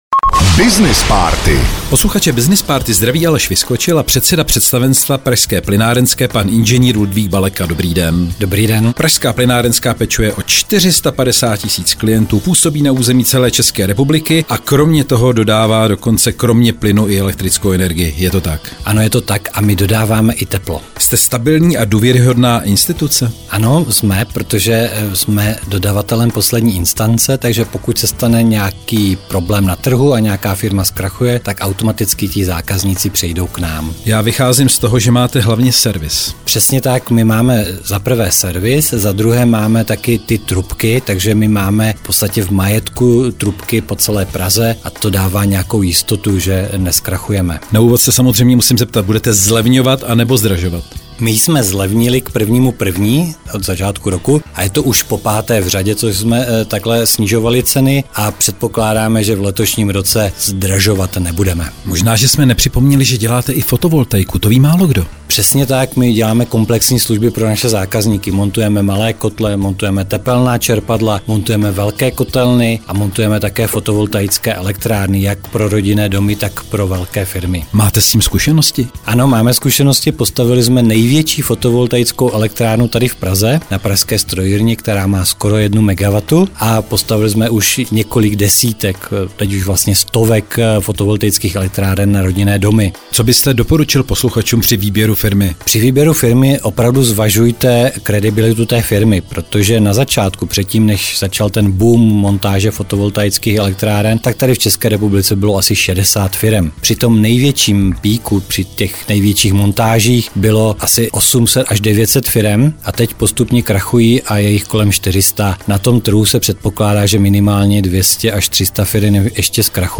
Veškerá témata rozhovorů na Frekvenci 1 jsou dostupná na uvedených odkazech:
Rozhovor 2
rozhovor_02.mp3